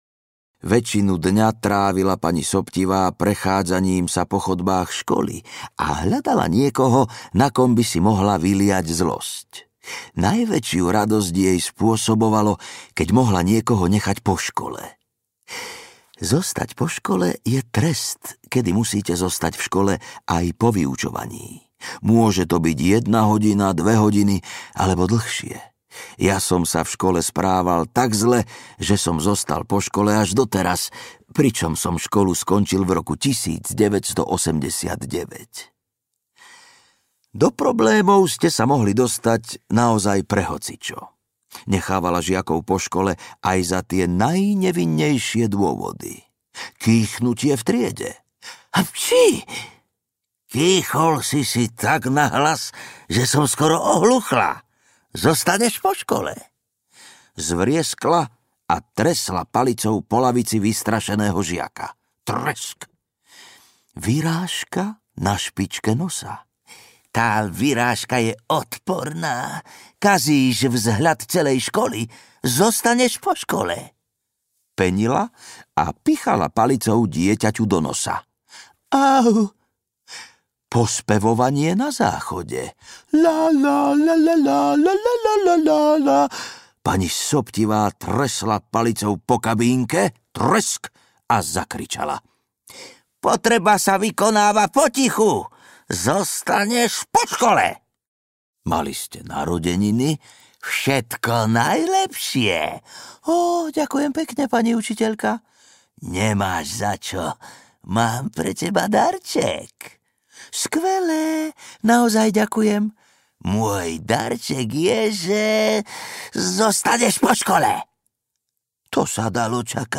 Najhorší učitelia na svete audiokniha
Ukázka z knihy